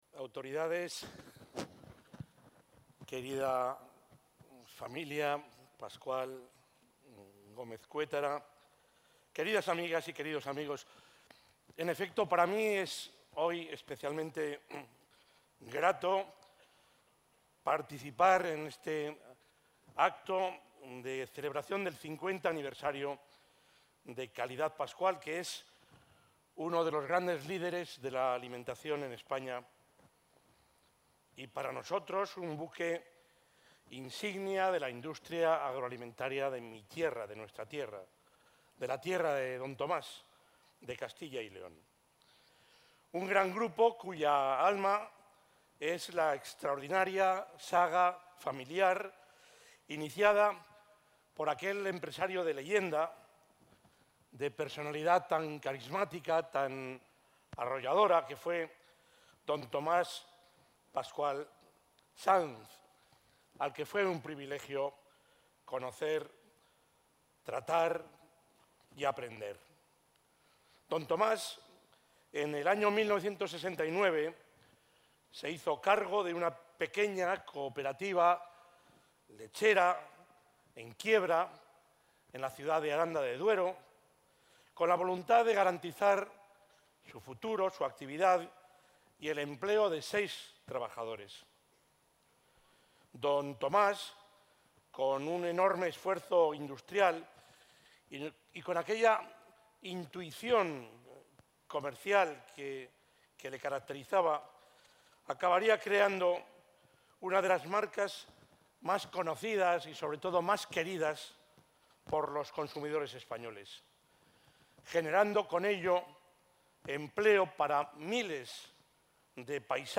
Durante su intervención en el acto de conmemoración del 50 aniversario de Calidad Pascual, el presidente de la Junta de Castilla y...
Audio presidente de la Junta.
El presidente de la Junta de Castilla y León, Juan Vicente Herrera, ha asistido al acto de conmemoración del 50 aniversario de Calidad Pascual, que se ha celebrado esta mañana en Madrid, donde ha destacado que se trata de uno de los líderes de la alimentación en España y uno de los buques insignia de la industria agroalimentaria de Castilla y León.